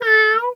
cat_2_meow_02.wav